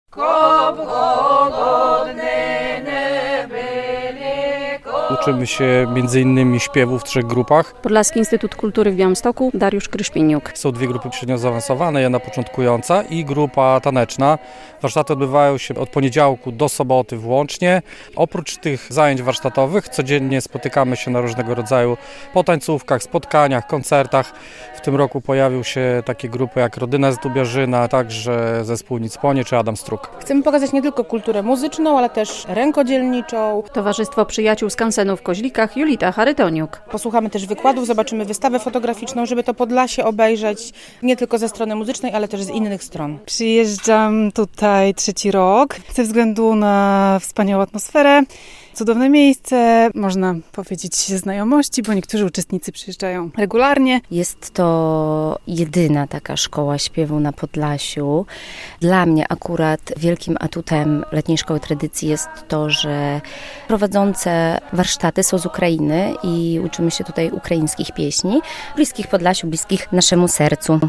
W skansenie w Koźlikach rozpoczęły się "Meandry - letnia szkoła tradycji" - relacja